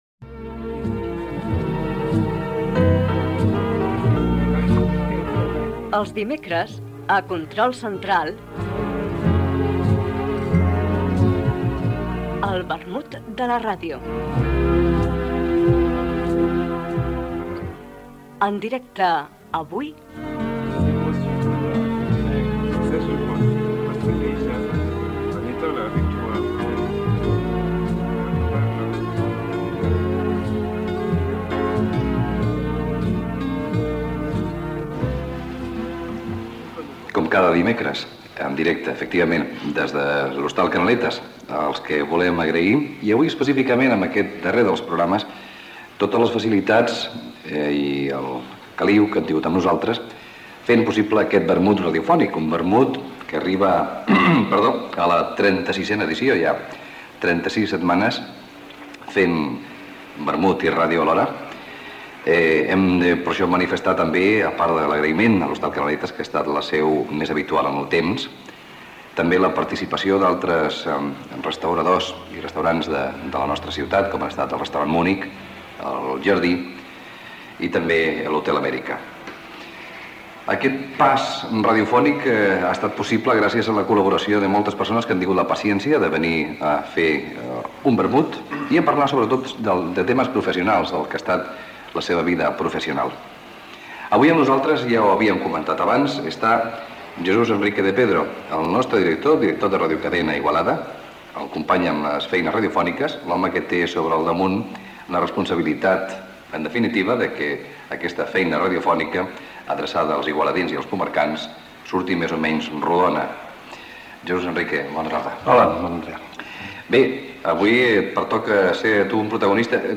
Última edició de l'espai "El vermut de la ràdio". Espai fet en directe des de l'Hostal Canaletas d'Igualda.